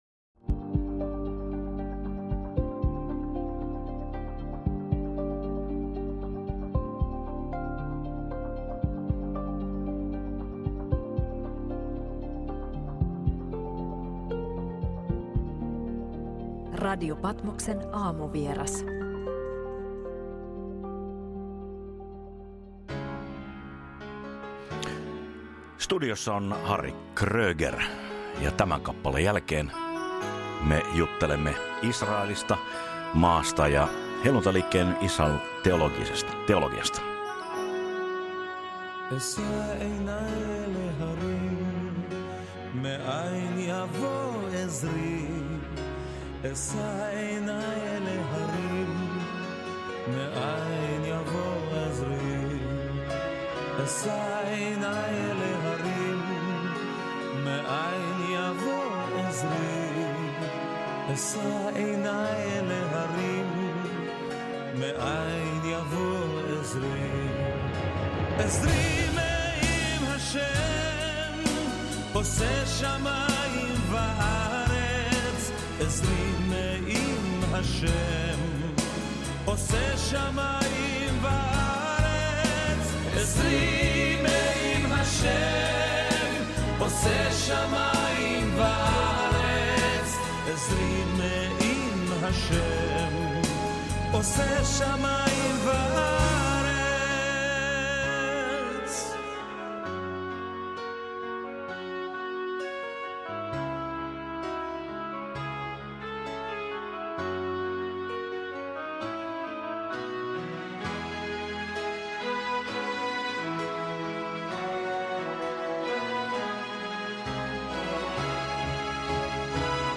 Kuuntele koko Aamuvieras-haastattelu kokonaisuudessaan tämän referaatin lopusta löytyvästä linkistä.